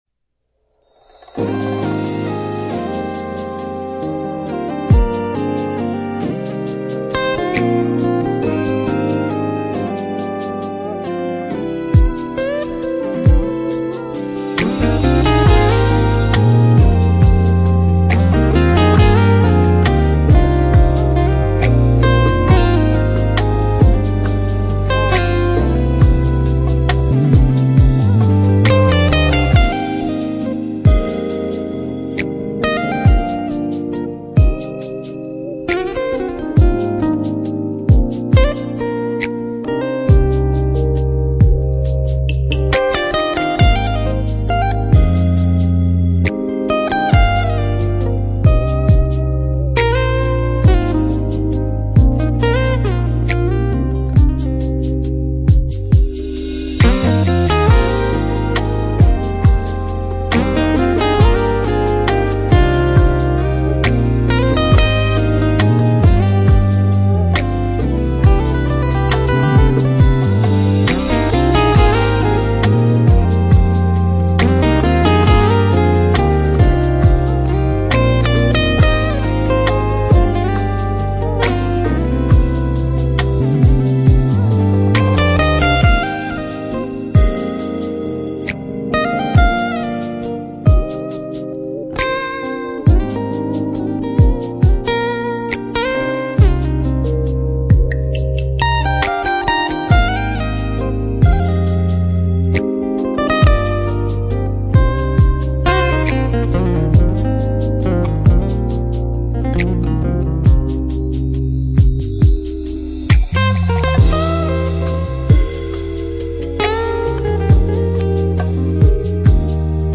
【爵士吉他】
音乐风格：Smooth Jazz